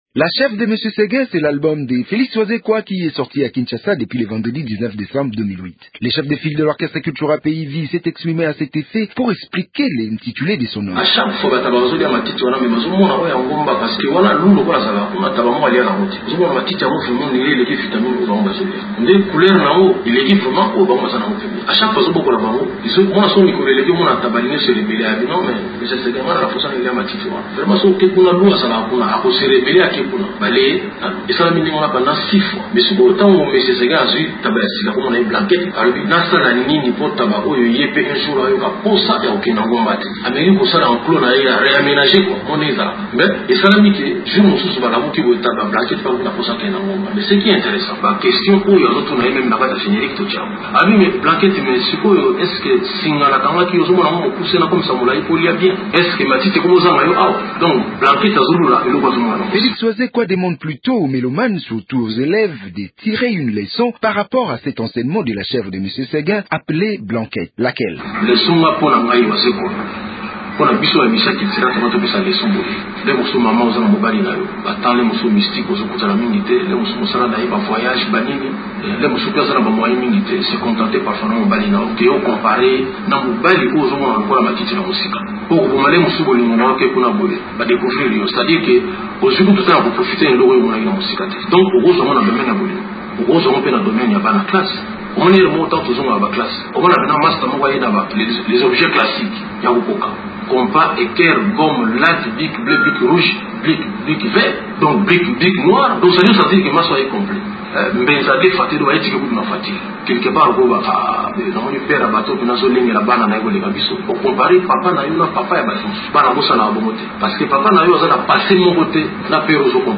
Félix Wazekwa répond dans cet entretien exclusif